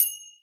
finger_cymbals_side_choke04
bell chime cymbal ding finger-cymbals orchestral percussion sound effect free sound royalty free Sound Effects